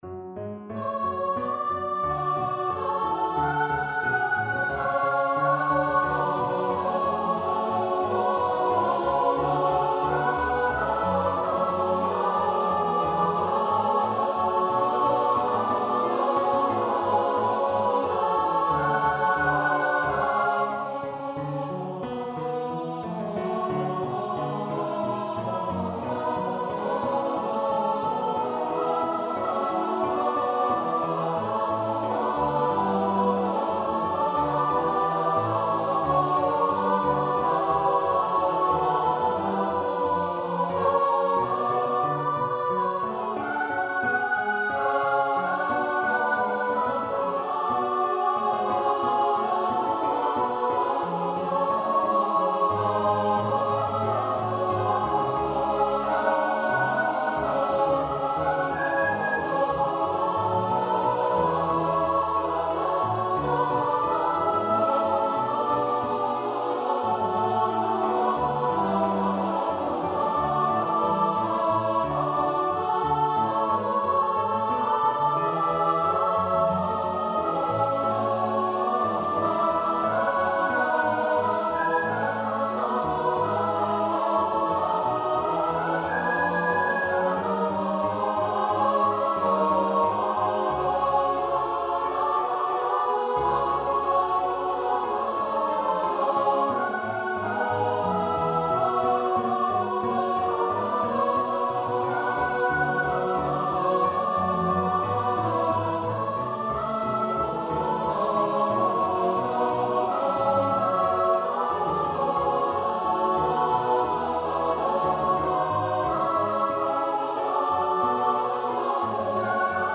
Free Sheet music for Choir (SSATB)
Choir  (View more Intermediate Choir Music)
Classical (View more Classical Choir Music)